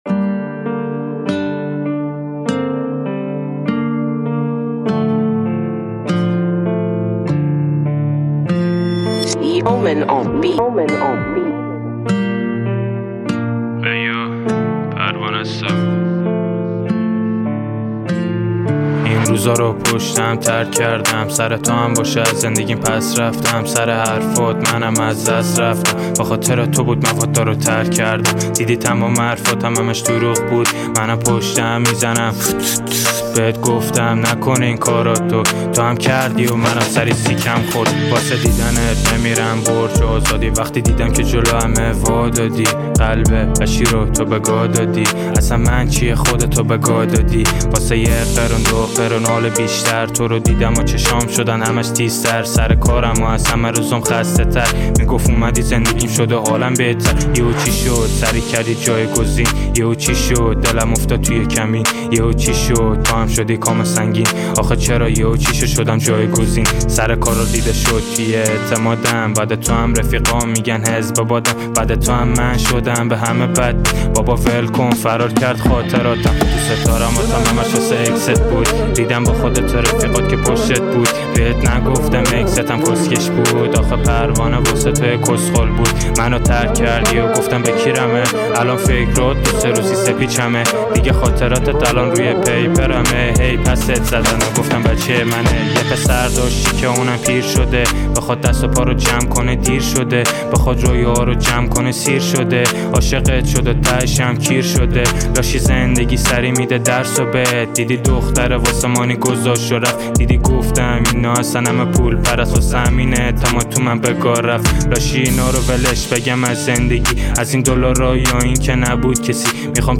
(Rapper)